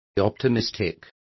Complete with pronunciation of the translation of optimistic.